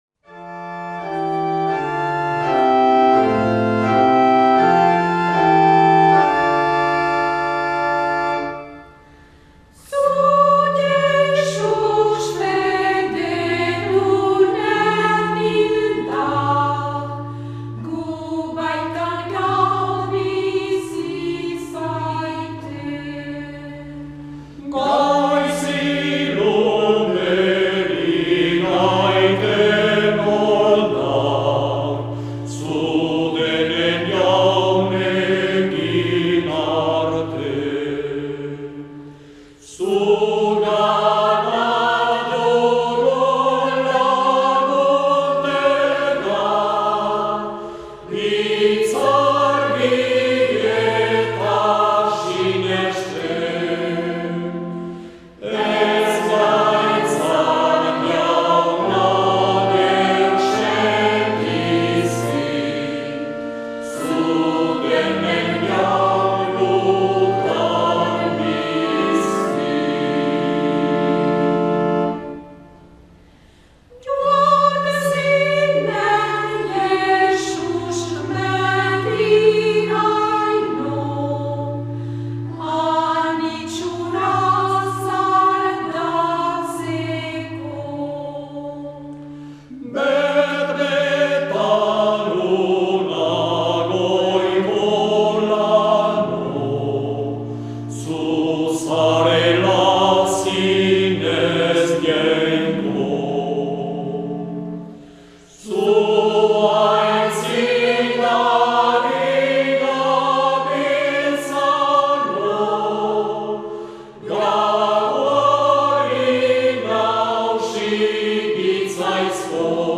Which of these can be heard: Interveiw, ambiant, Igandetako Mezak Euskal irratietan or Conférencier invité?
Igandetako Mezak Euskal irratietan